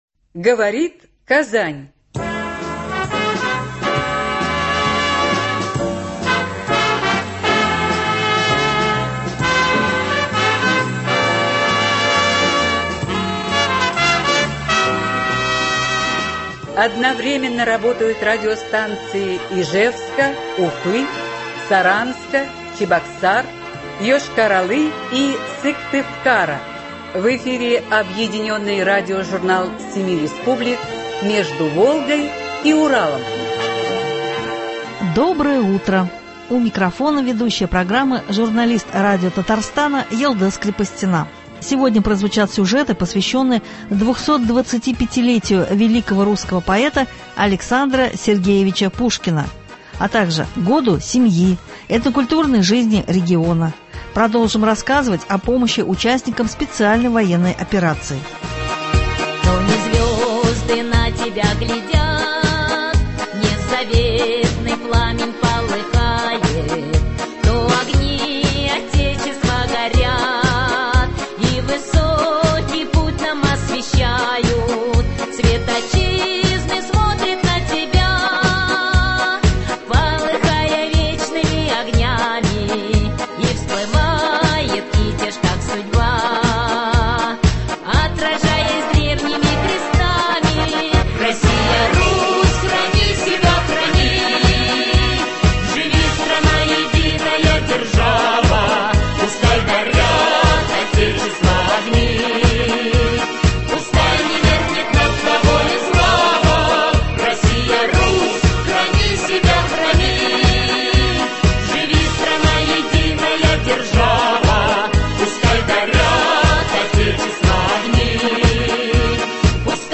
Объединенный радиожурнал семи республик. Сегодня прозвучат сюжеты , посвященные 225 — летию великого русского поэта А.С. Пушкина , Году семьи, этнокультурной жизни региона. Продолжим рассказывать о помощи участникам СВО.